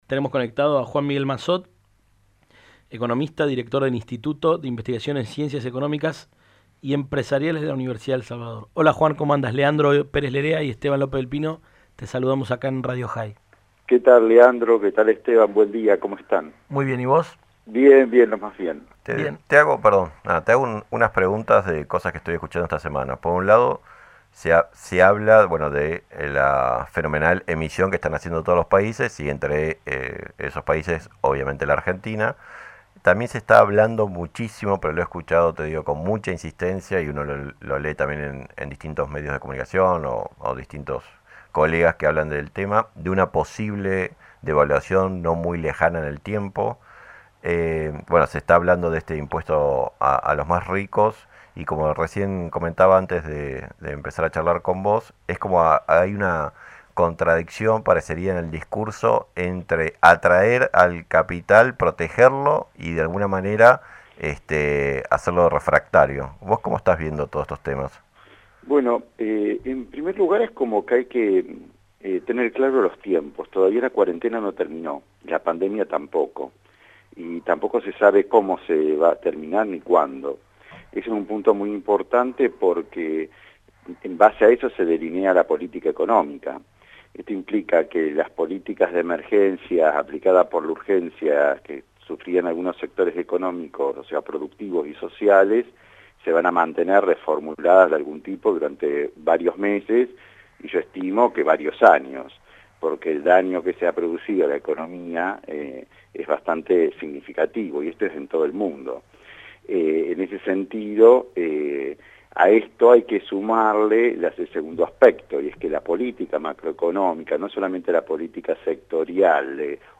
En una entrevista con Radio Jai